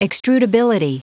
Prononciation du mot extrudability en anglais (fichier audio)
Prononciation du mot : extrudability